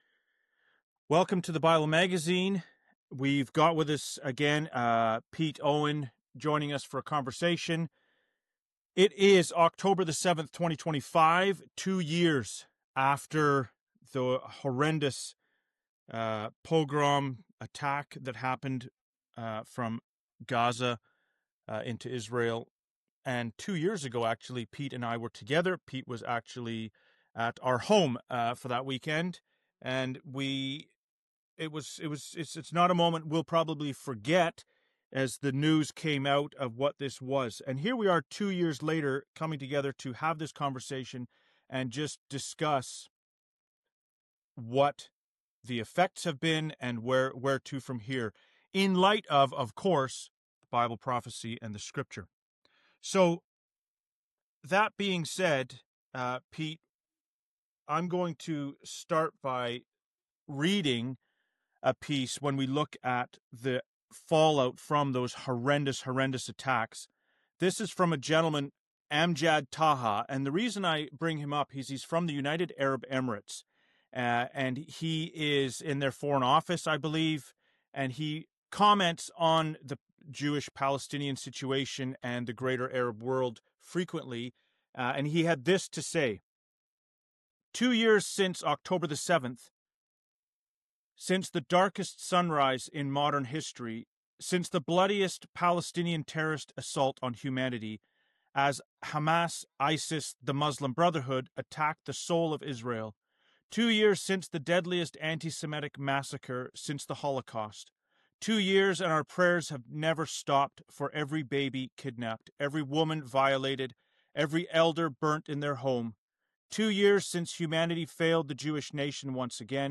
Two Years on from October 7th An unscripted discussion on current events in light of Bible Prophecy.